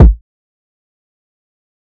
{Kick} MurdaKick.wav